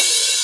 Index of /VEE/VEE2 Cymbals/VEE2 Rides
VEE2 Ride 06.wav